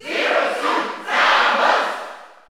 Category: Crowd cheers (SSBU) You cannot overwrite this file.
Zero_Suit_Samus_Cheer_German_SSBU.ogg